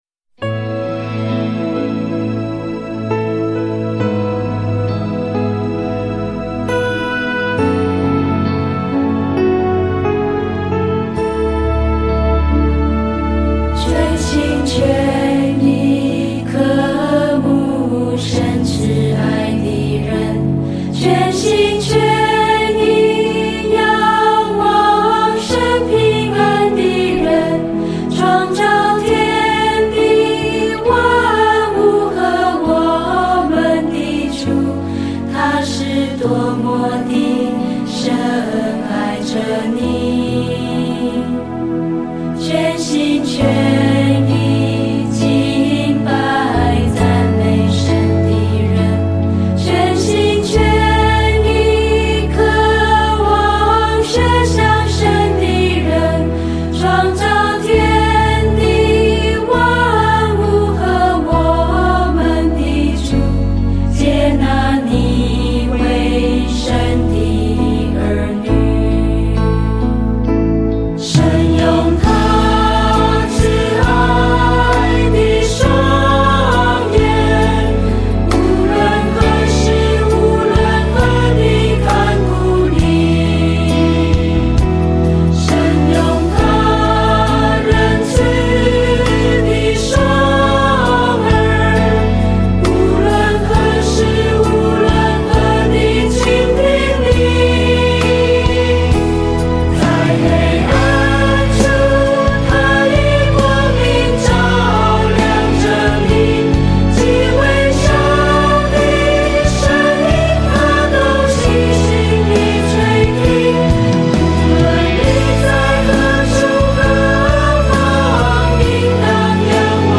赞美诗《应当单单仰望主》